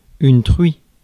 Ääntäminen
Synonyymit cochon cochonne Ääntäminen France: IPA: /tʁɥi/ Haettu sana löytyi näillä lähdekielillä: ranska Käännös Substantiivit 1. porkino Suku: f .